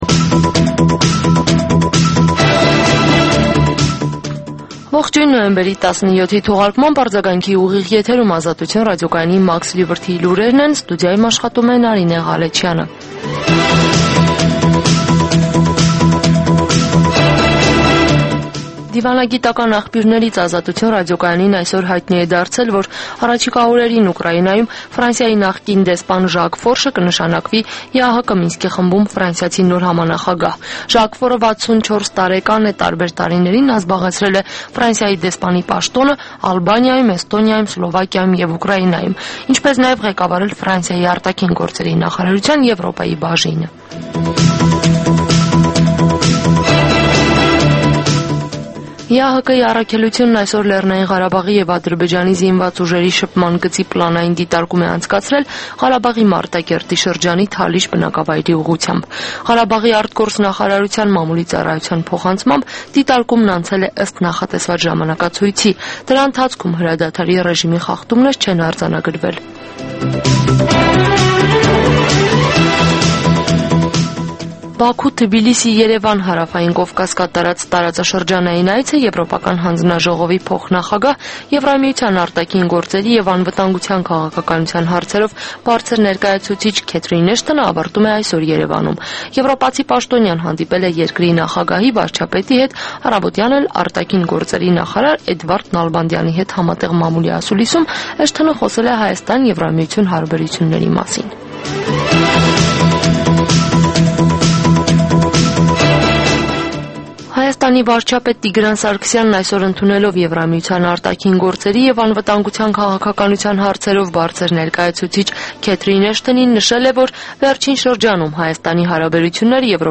Լուրեր
Տեղական եւ միջազգային վերջին լուրերը ուղիղ եթերում: